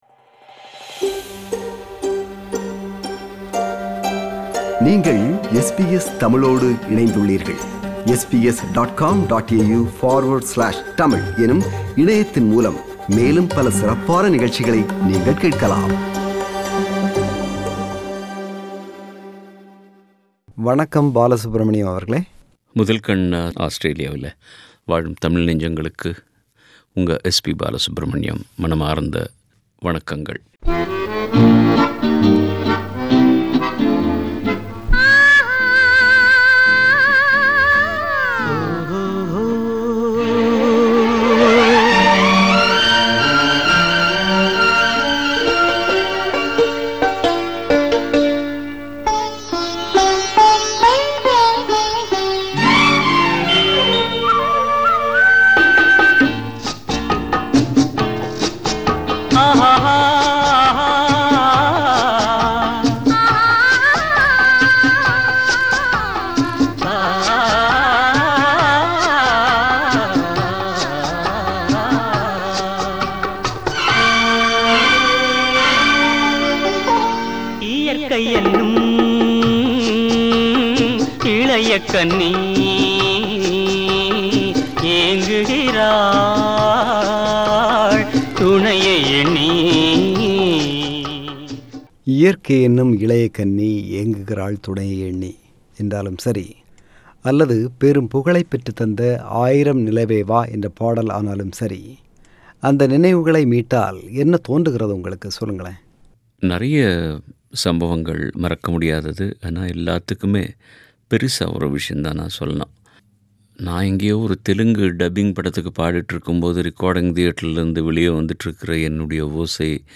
மறைந்த பிரபல பின்னணிப்பாடகர் S P பாலசுப்ரமணியம் அவர்கள் SBS தமிழ் ஒலிபரப்புக்கு சுமார் ஆறு ஆண்டுகளுக்கு முன்பு வழங்கிய நேர்முகத்தின் மறுபதிவு.